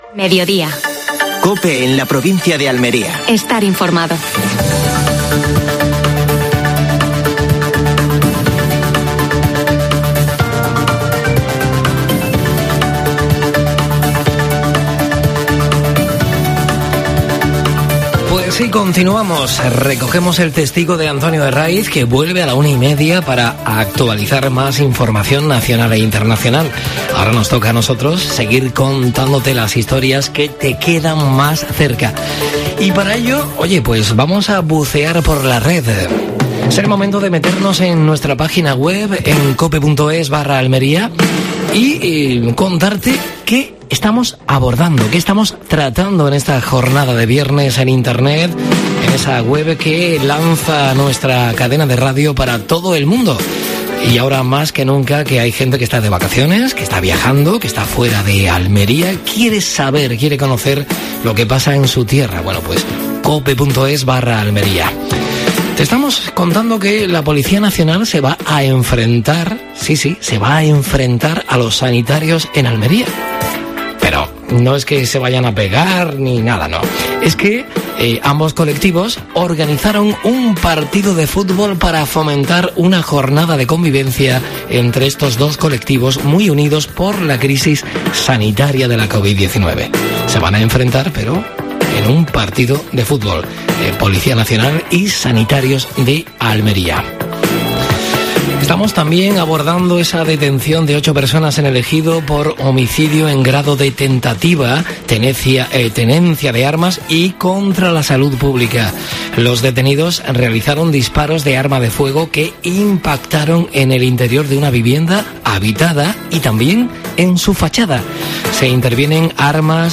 AUDIO: Actualidad en Almería. La UDA comienza la pretemporada con su primer fichaje. Entrevista